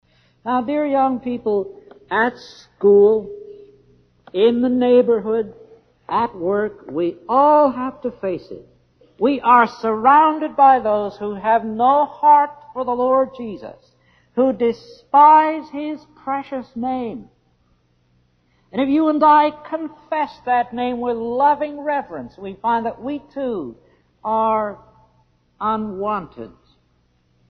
They go from the 1960s to the 80s, are of varying degrees of sound quality, but are pretty much all giving exactly the same message, despite there being about sixty of them.